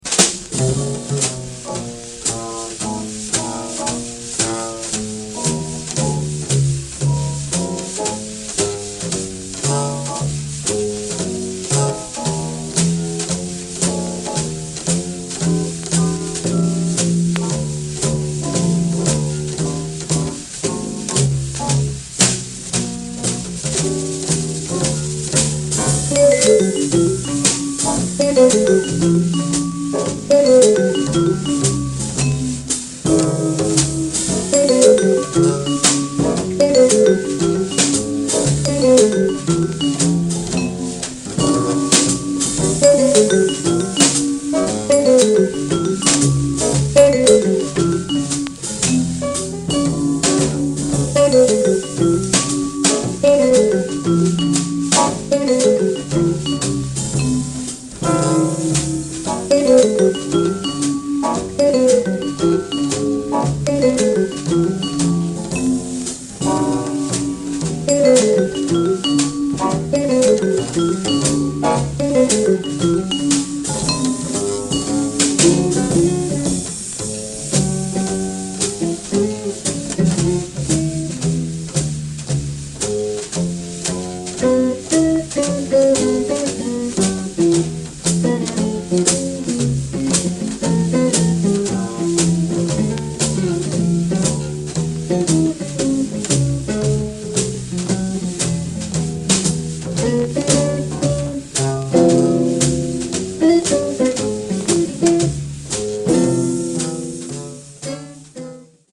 vibes
guitar